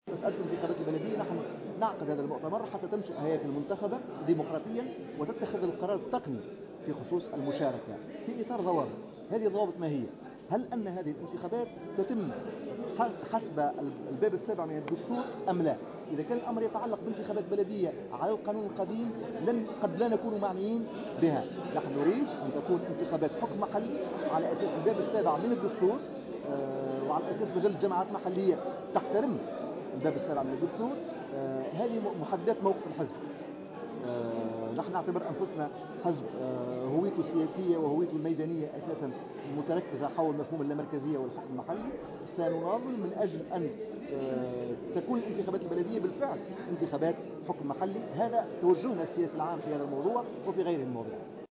قال الأمين العام لحراك تونس الإرادة، عدنان منصر اليوم على هامش انعقاد أول مؤتمر انتخابي للحزب إن الهياكل المنتخبة هي التي ستحدّد موقف الحزب من المشاركة في الانتخابات البلدية المقبلة من عدمه.
وأوضح في تصريح لمراسل "الجوهرة أف أم" أن مشاركة "حراك تونس الإرادة" في هذه الانتخابات ستكون في إطار ضوابط، من ذلك أن تحترم هذه الانتخابات الباب السابع من الدستور.